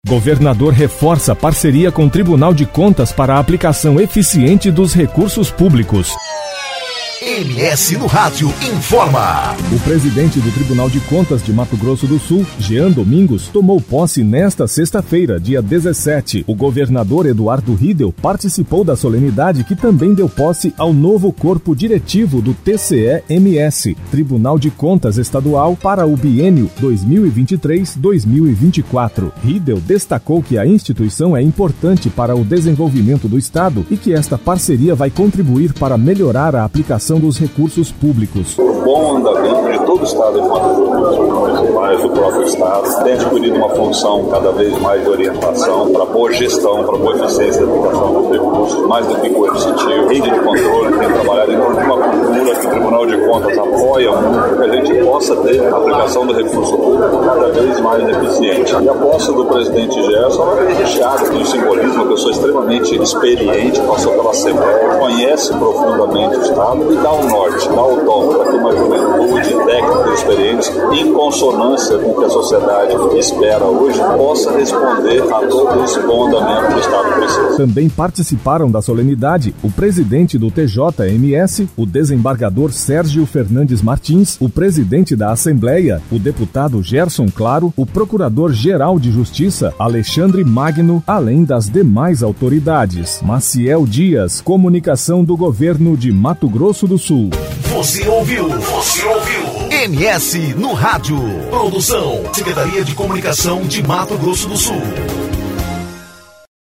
Governador discursa durante solenidade de posse no TCE-MS